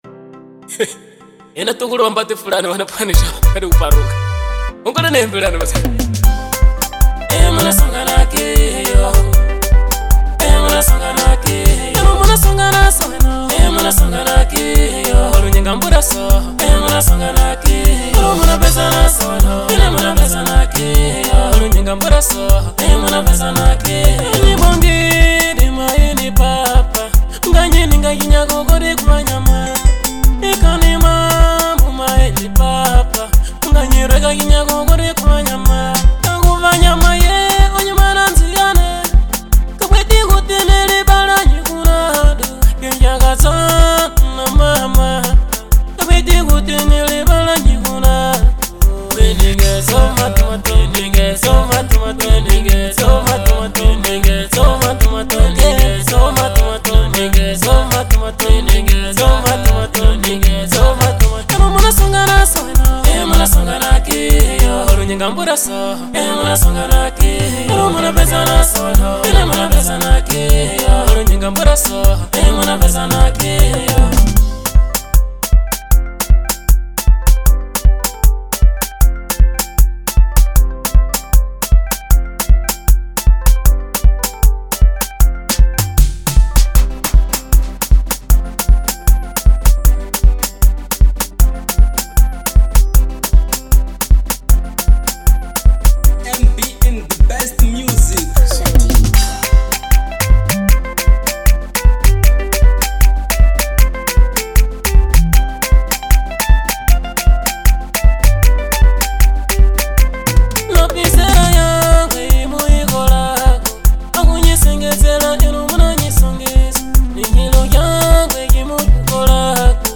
Marrabenta